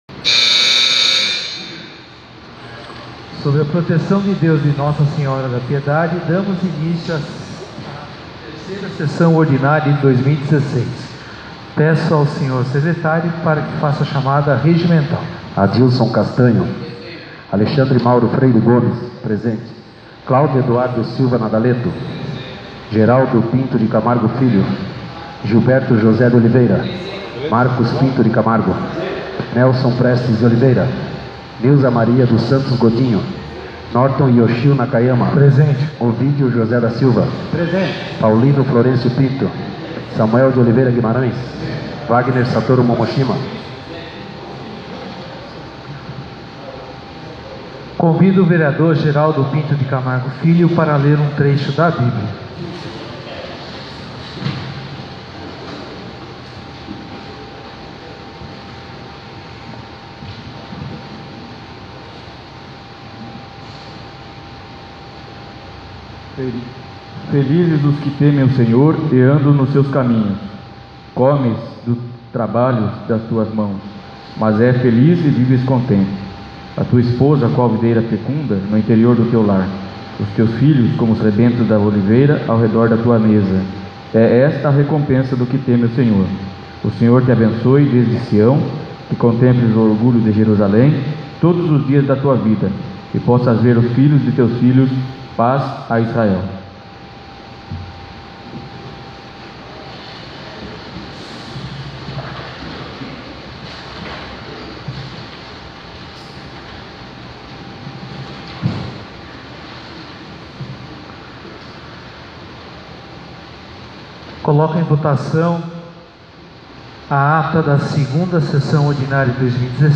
3ª Sessão Ordinária de 2016 — Câmara Municipal de Piedade